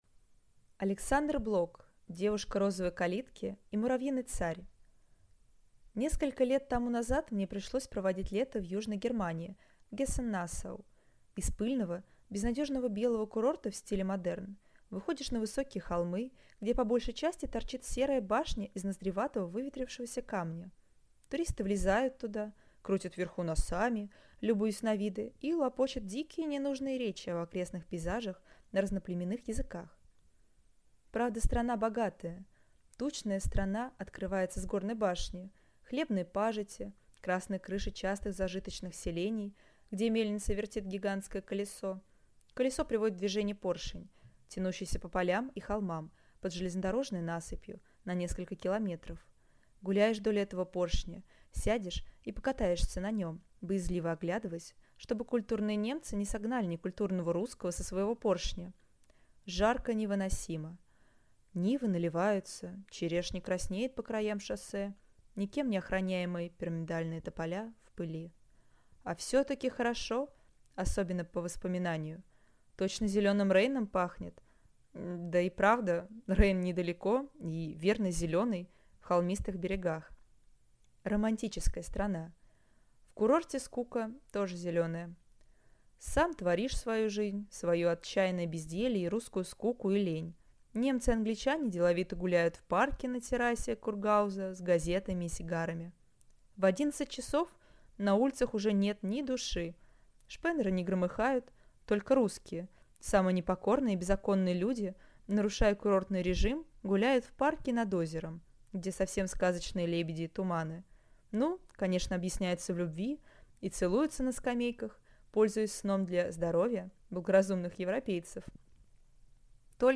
Аудиокнига Девушка розовой калитки и муравьиный царь | Библиотека аудиокниг